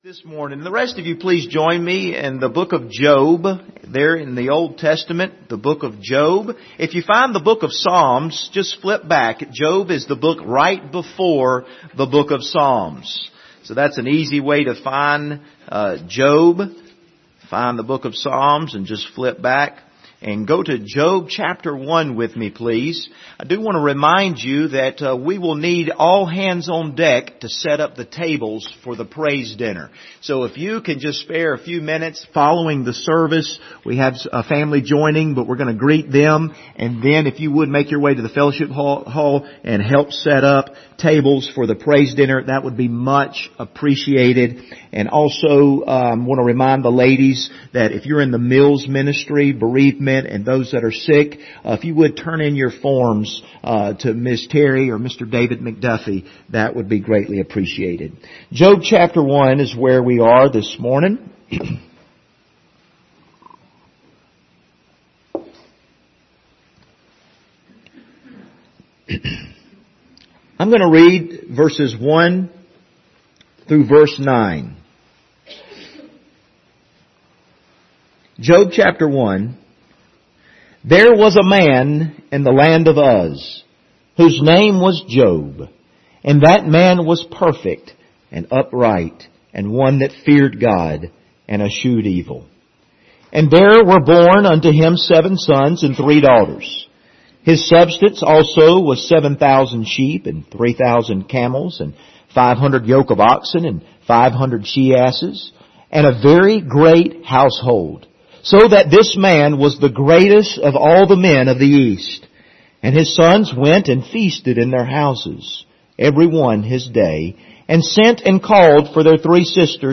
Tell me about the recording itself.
Job 1:1-9 Service Type: Sunday Morning Topics